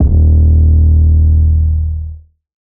SOUTHSIDE_808_fixmypc_D#.wav